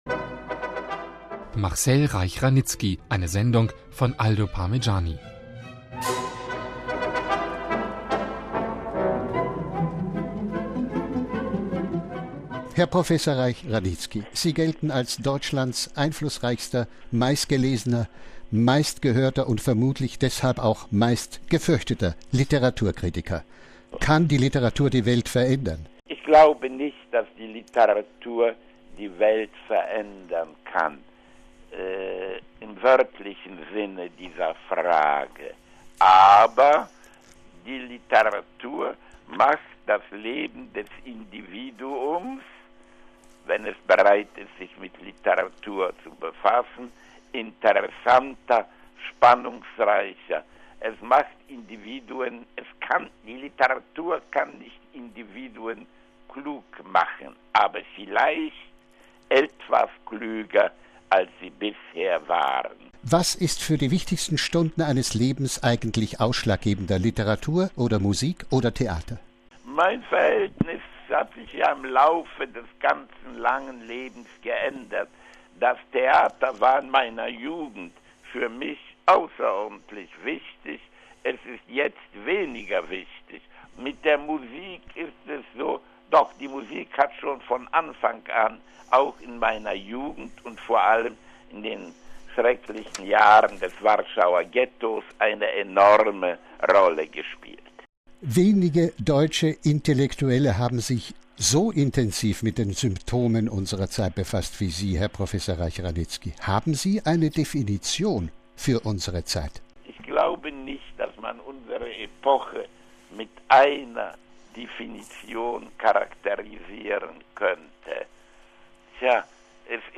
Reich-Ranicki, der am Mittwoch im Alter von 93 Jahren verstarb, äußerte sich in einem Interview mit Radio Vatikan aber nicht nur zur Literatur, sondern auch zu Religion.